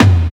108 TOM LO-R.wav